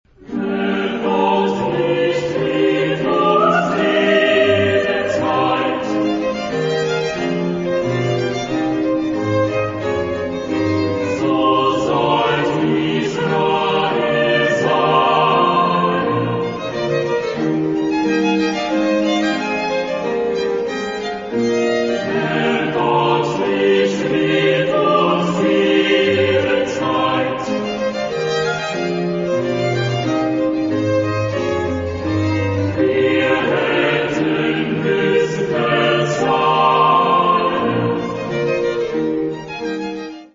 Genre-Stil-Form: geistlich ; Choral ; Kantate
Chorgattung: SATB  (4 gemischter Chor Stimmen )
Instrumente: Violinen (2) ; Basso Continuo
Tonart(en): g-moll
von Dresdner Kammerchor gesungen unter der Leitung von Hans-Christoph Rademann